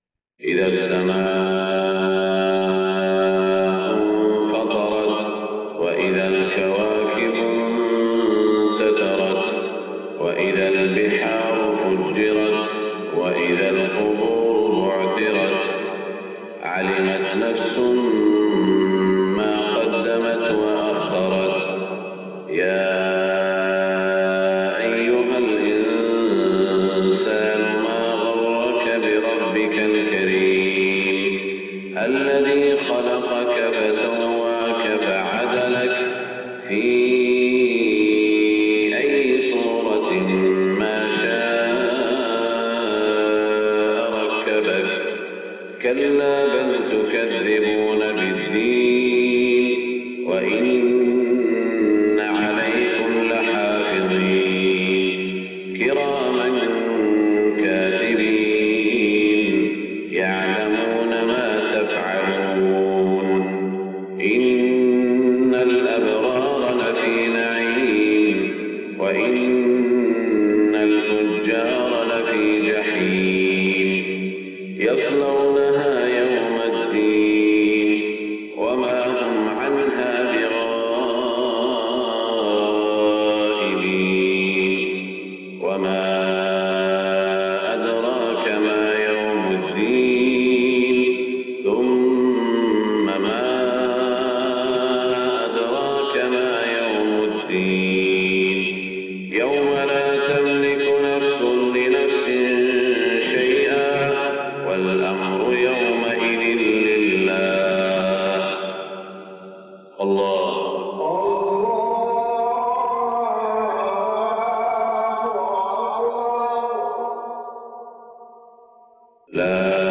صلاة الفجر 18 ذو القعدة 1427هـ سورتي الانفطار و البلد > 1427 🕋 > الفروض - تلاوات الحرمين